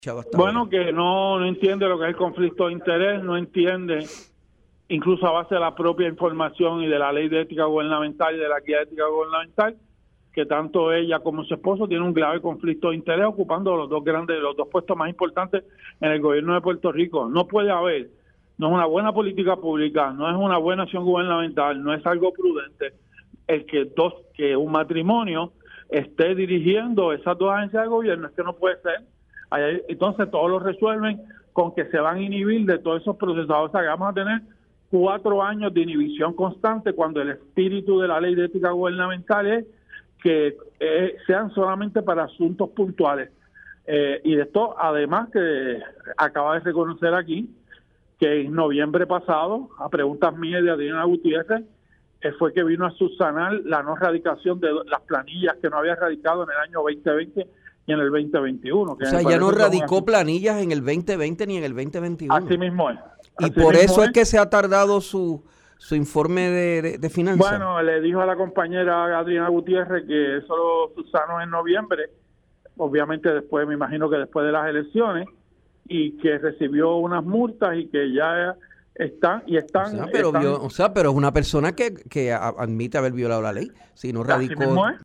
El representante Denis Márquez indicó en Tiempo Igual que durante la vista pública en la Cámara de Representantes confirmación de la secretaria de Estado, Verónica Ferraiouli, la licenciada reconoció que no había radicado planillas de Contribución de Ingresos en el 2020 y 2021.